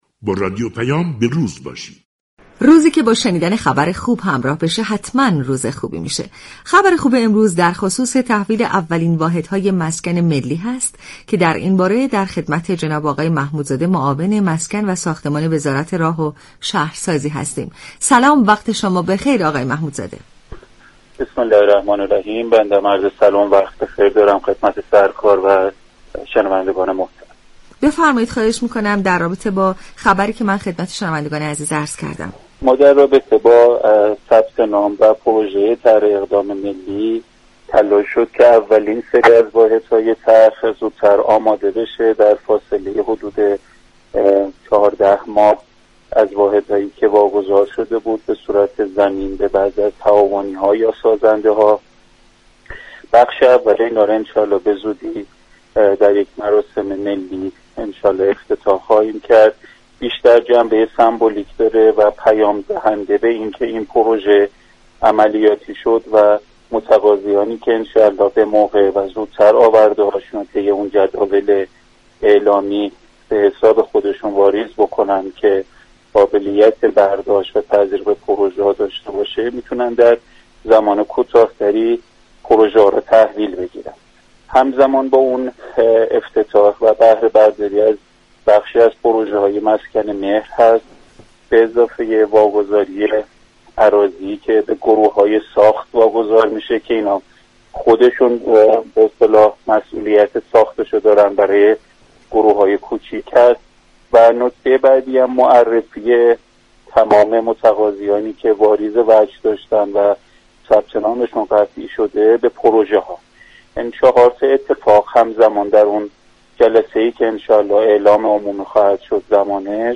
محمودزاده معاون مسكن و ساختمان وزارت راه و شهرسازی در گفتگو با رادیو پیام ، جزئیات واگذاری اولین بخش از واحدهای طرح مسكن ملی را بازگو كرد .